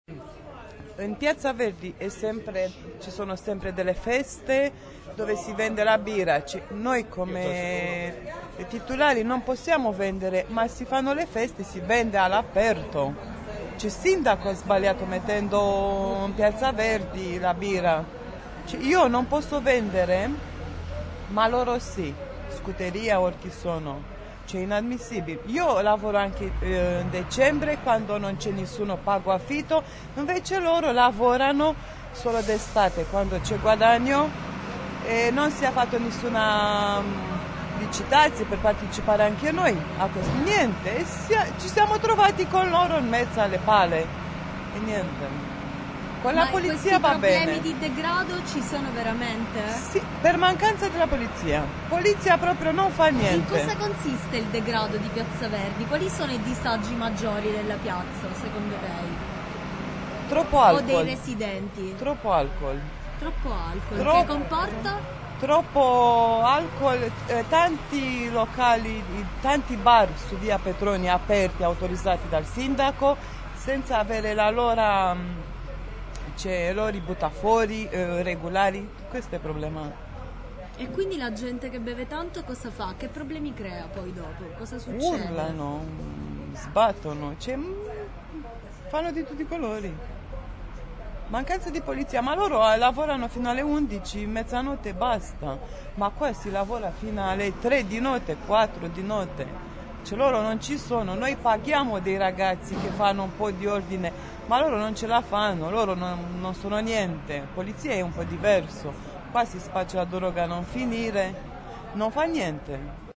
Da un giro in piazza, registratore alla mano, e due chiacchiere con chi la piazza la vive ma anche con chi in piazza ci vive, sono emersi i diversi punti di vista che alimentano il dibattito.
Spostandoci in via Petroni, abbiamo sentito il parere di una commerciante